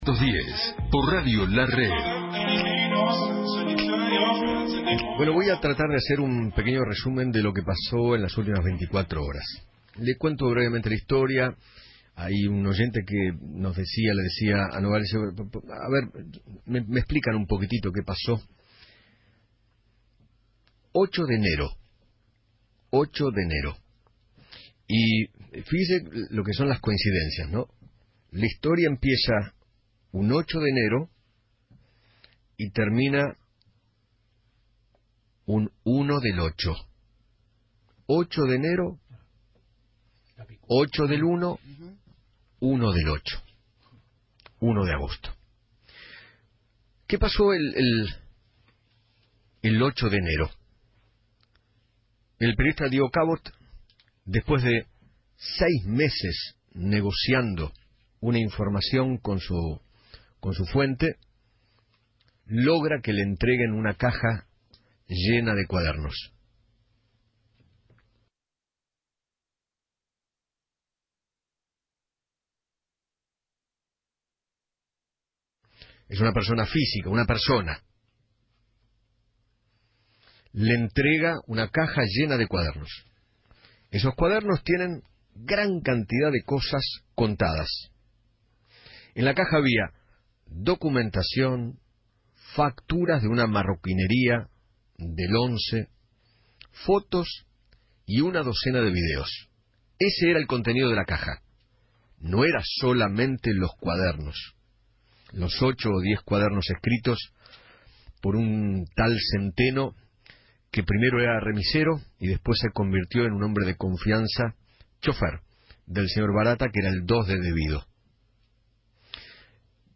Eduardo Feinmann realizó su editorial haciendo un repaso del modus operandi de los cuadernos del escándalo.